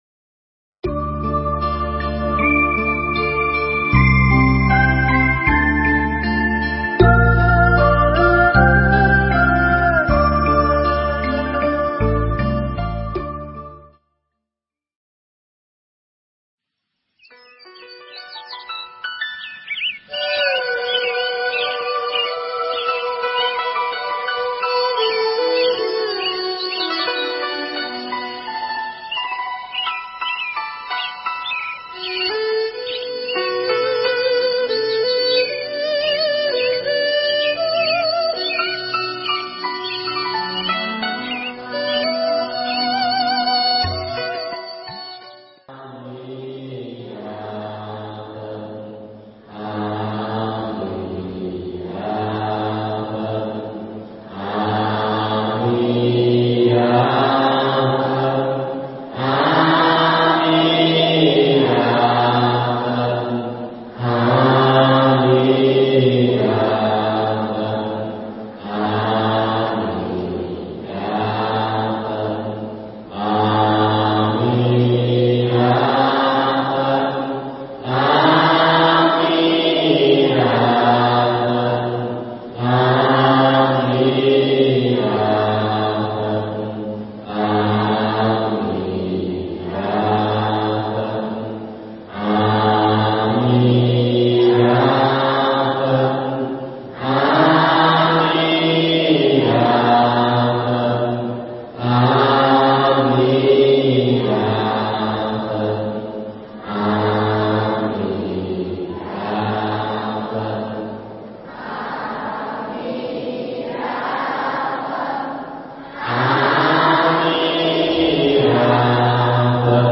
Ơn Cha Nghĩa Mẹ – Thuyết pháp